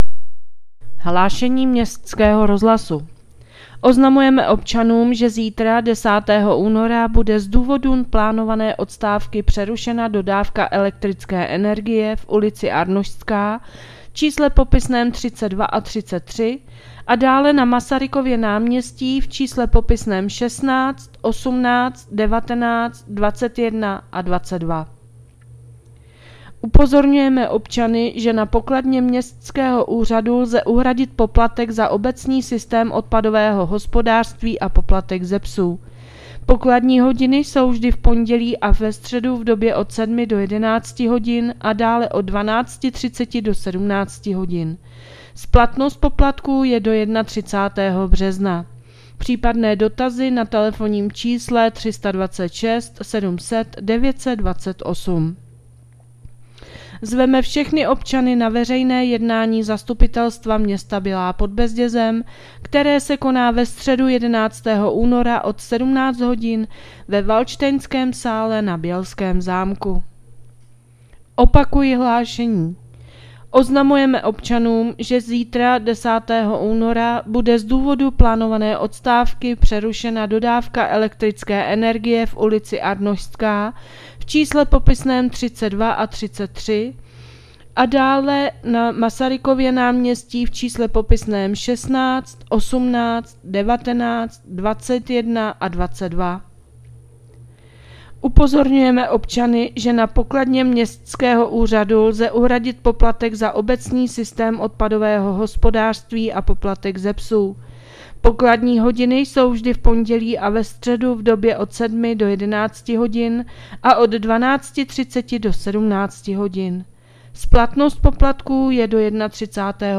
Hlášení městského rozhlasu 9.2.2026